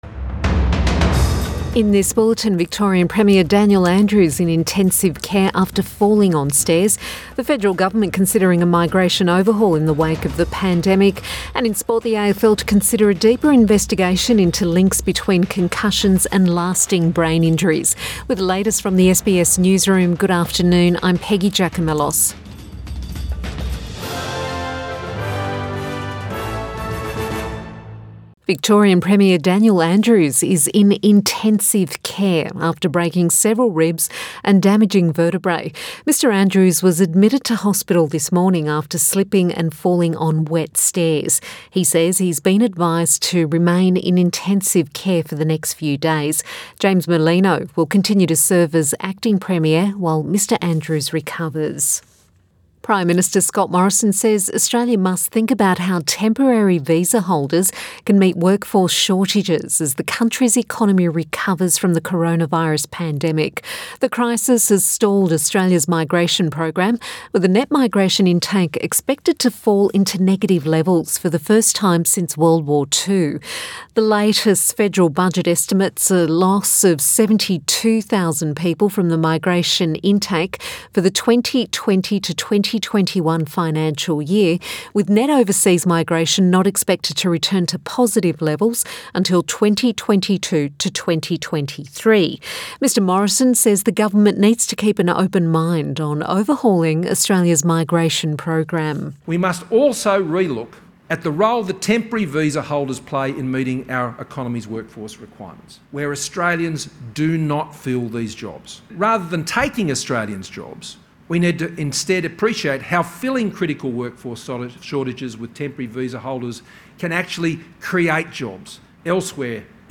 PM bulletin 9 March 2021